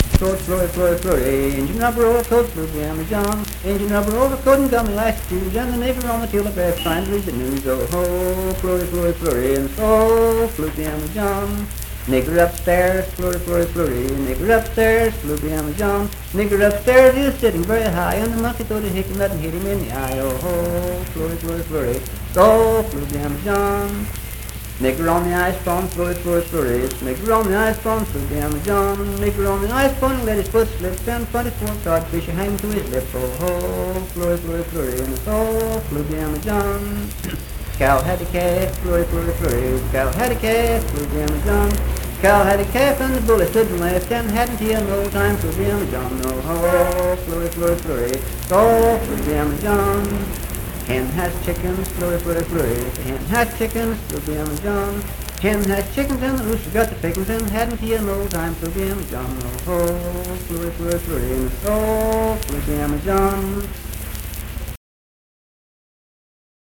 Unaccompanied vocal music performance
Minstrel, Blackface, and African-American Songs
Voice (sung)
Calhoun County (W. Va.)